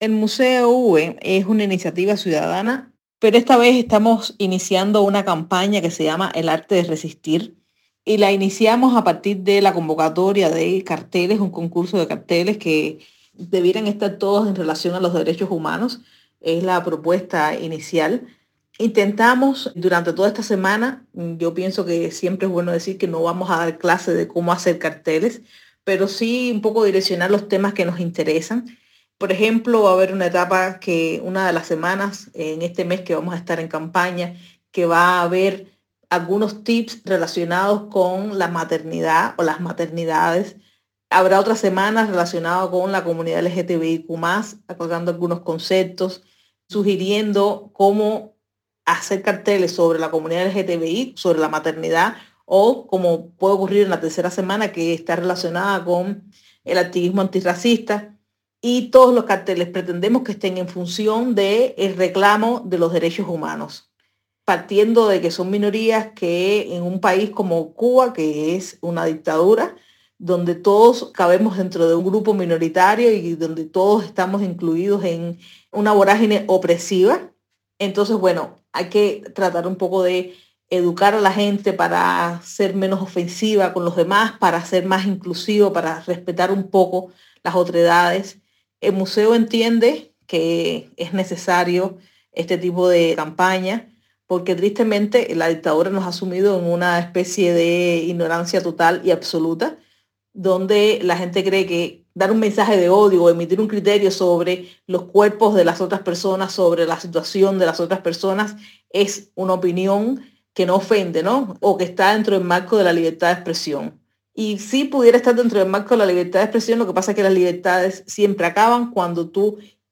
Declaraciones a Martí Noticias